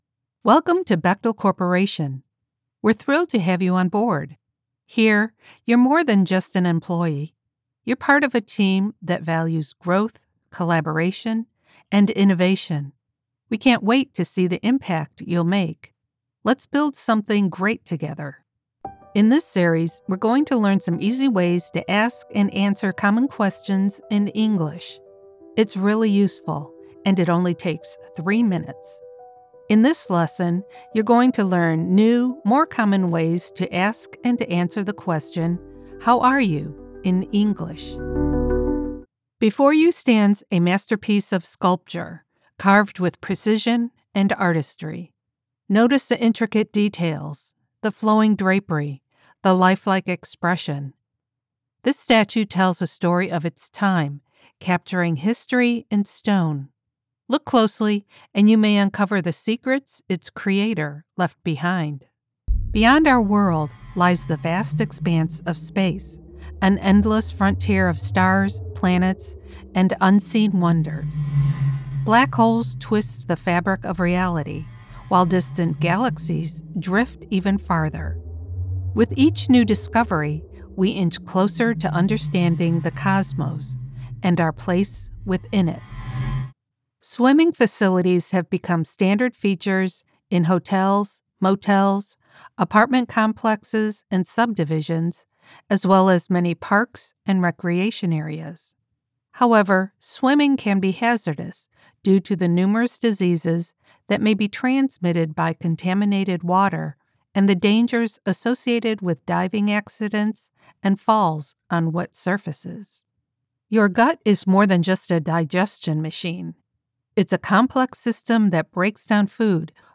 Professional Female Voice Over Talent
Corporate Narration Demo
Let’s work together to bring your words to life with my conversational, authoritative and articulate voice.
CorpNarration.mp3